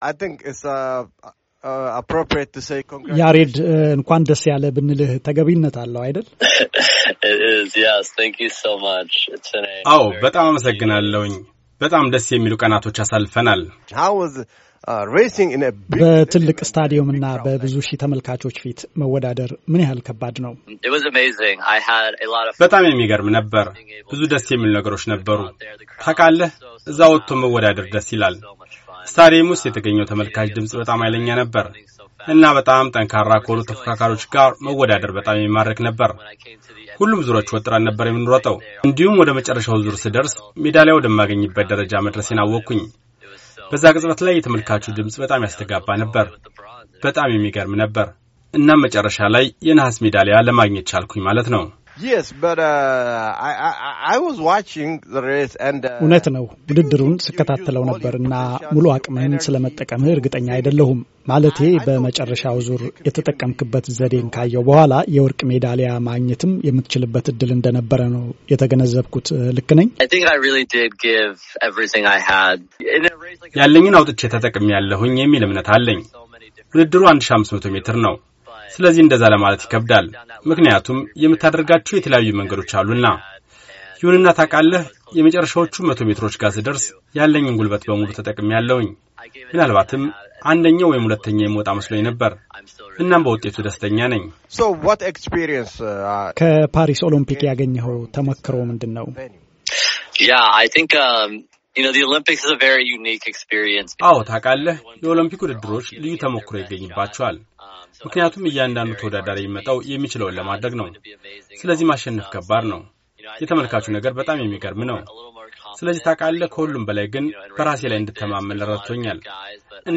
ወደ ፓሪስ ደውሎ አትሌት ያሬድን አነጋግሮ ያዘጋጀው ሪፖርት ቀጥሎ ይቀርባል፣